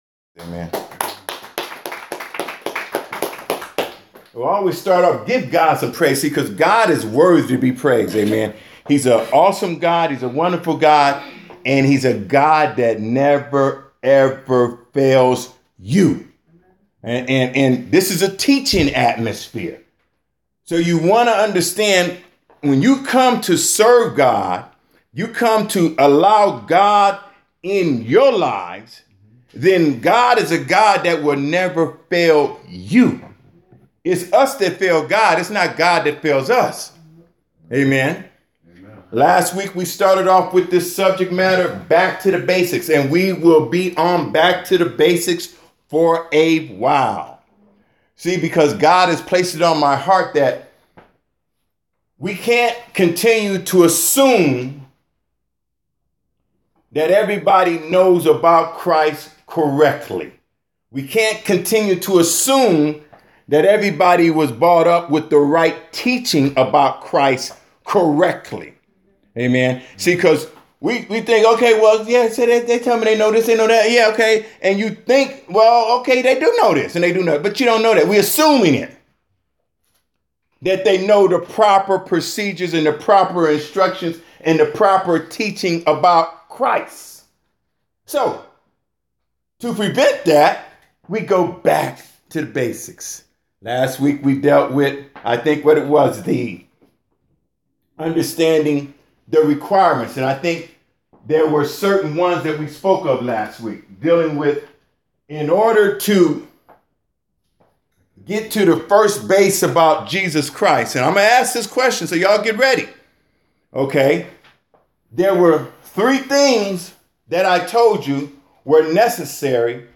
2016 Back to the Basic of Faith Preacher